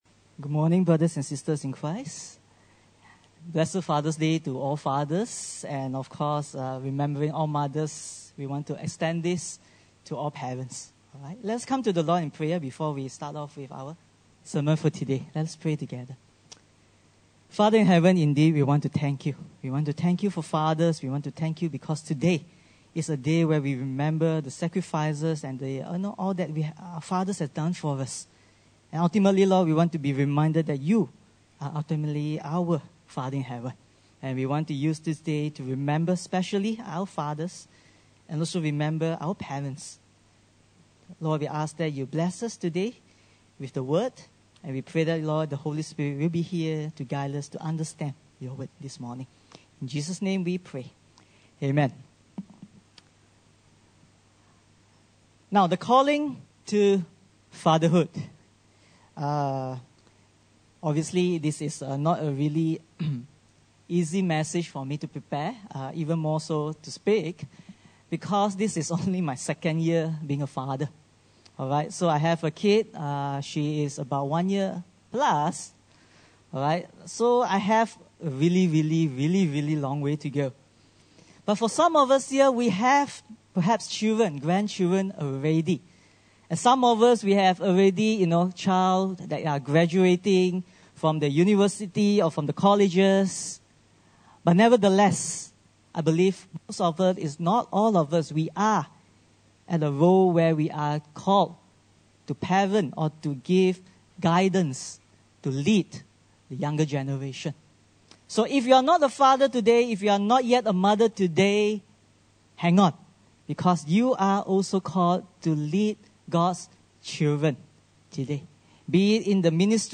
Passage: Deuteronomy 6:4-9 Service Type: Sunday Service